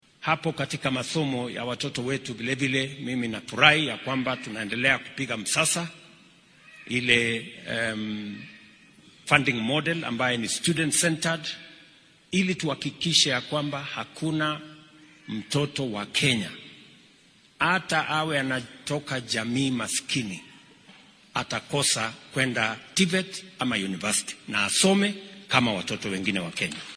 Xilli uu ismaamulka Uasin Gishu uga qayb galay munaasabad kaniiseed ayuu William Ruto madaxda ku boorriyay in ay xaqiijiyaan ka shaqeynta midnimada kenyaanka.